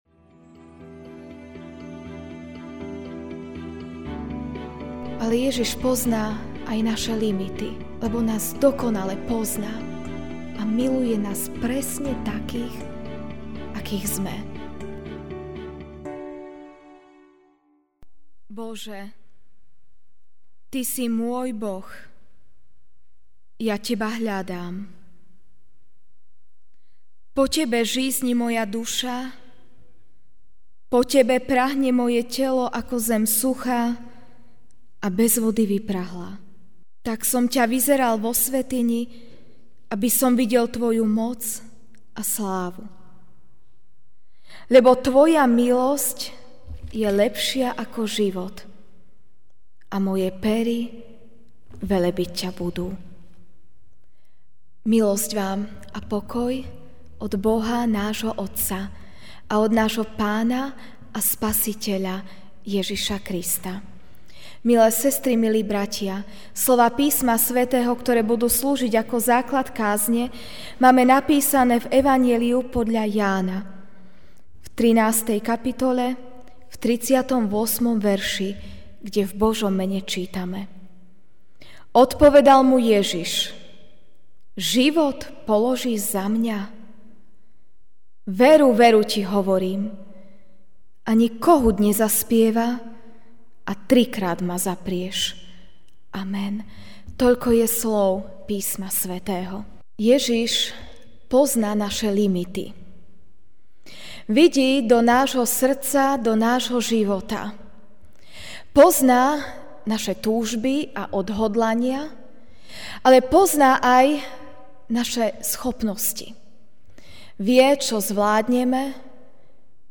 Večerná kázeň: Ježiš nás pozná, On pozná naše limity.